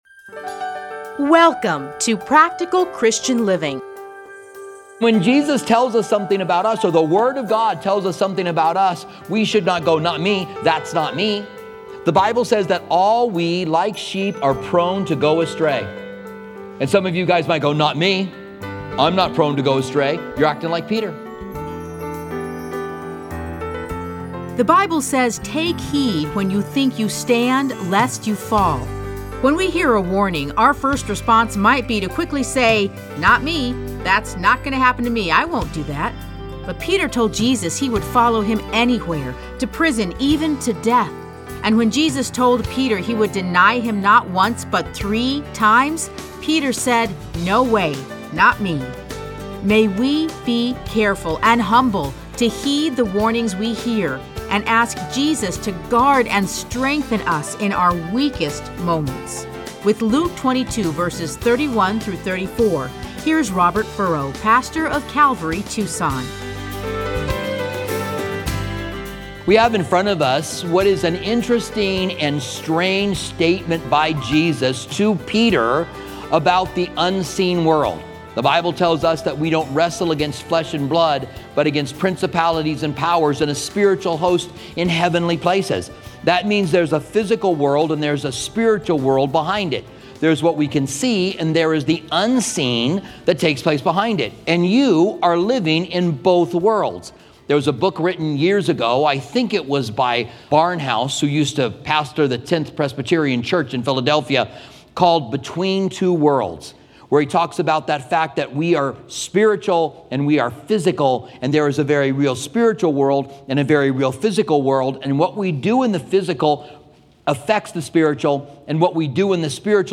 Listen to a teaching from Luke 22:31-34.